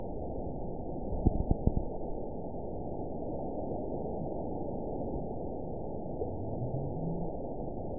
event 917067 date 03/17/23 time 22:56:59 GMT (2 years, 1 month ago) score 9.41 location TSS-AB04 detected by nrw target species NRW annotations +NRW Spectrogram: Frequency (kHz) vs. Time (s) audio not available .wav